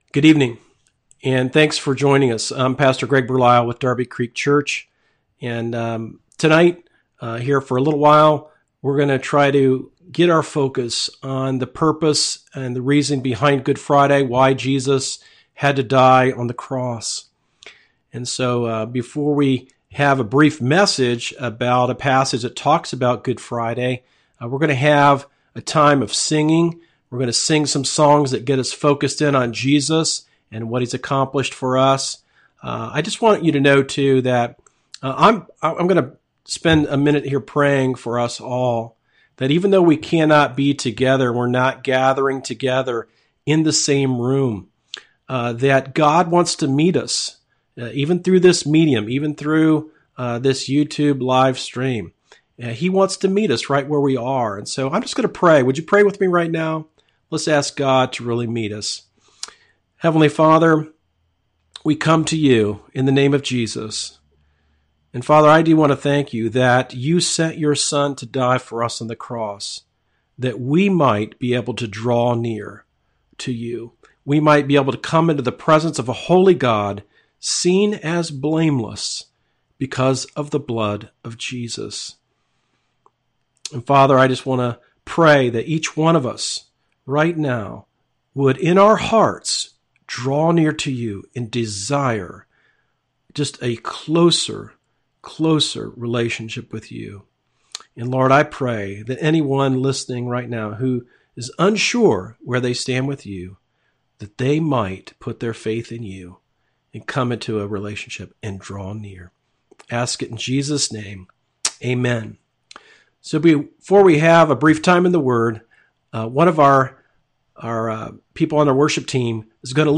Sermons that are not part of a series